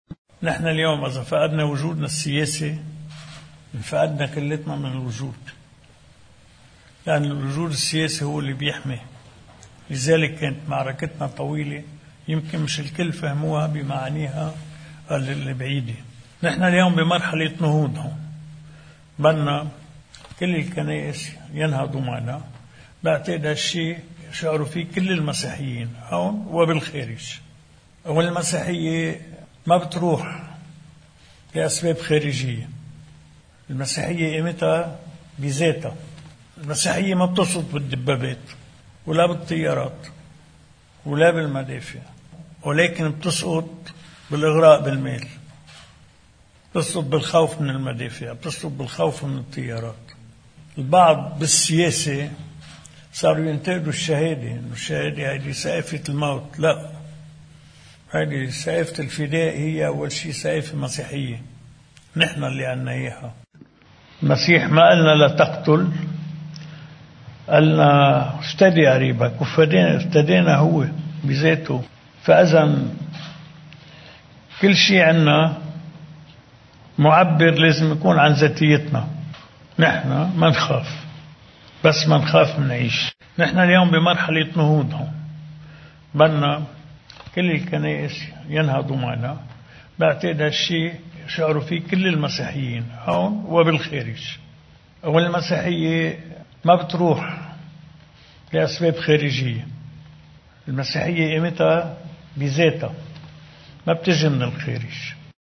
مقتطف من حديث الرئيس عون أمام وفد اللجنة التنفيذية لمجلس كنائس الشرق الأوسط: